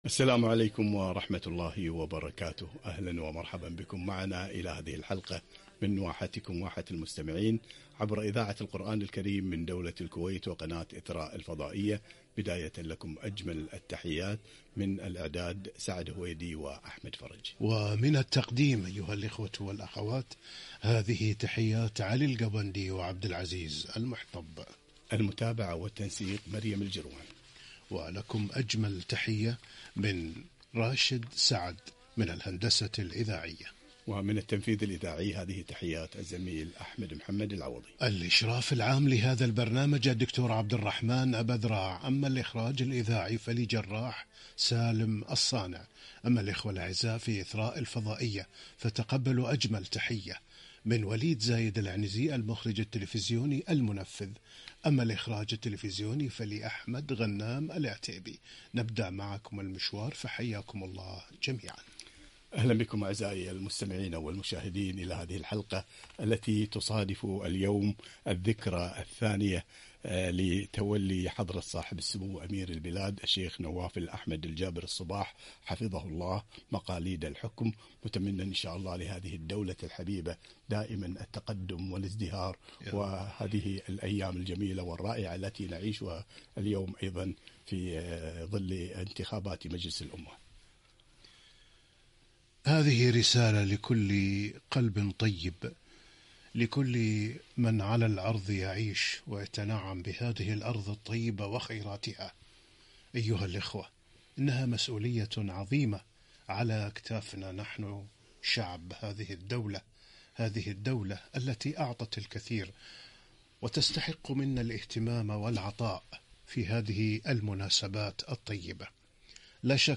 الأذكار حصن المسلم - لقاء عبر إذاعة القرآن الكريم